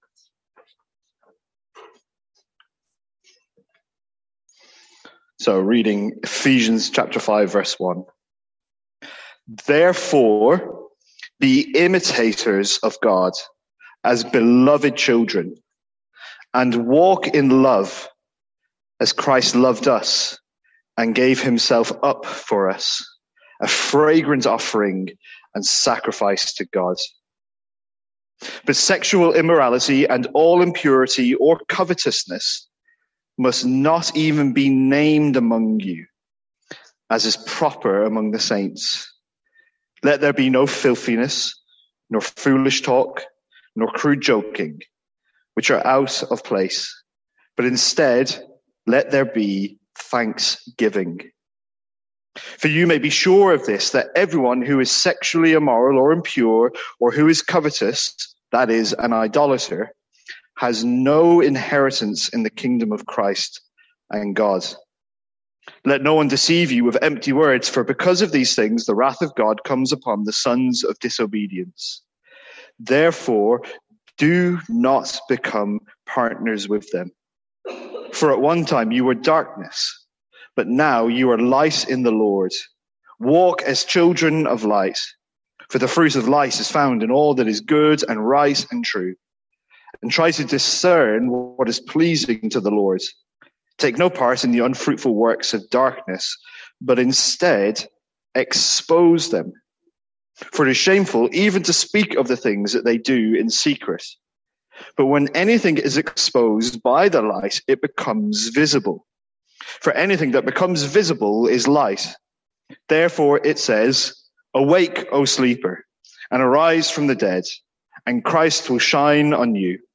Sermons | St Andrews Free Church
From our morning series in Ephesians.